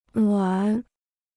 暖 (nuǎn): warm; to warm.